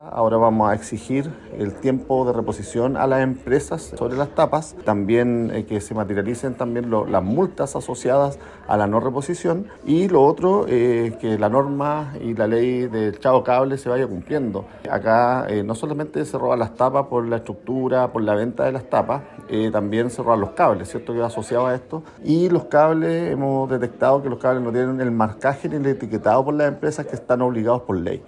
Desde el año pasado a la fecha, el municipio de Concepción ha tenido que reponer 256 tapas, con un gasto que alcanza los 55 millones de pesos, dijo el alcalde Héctor Múñoz.
cuna-tapas-alcalde-orde.mp3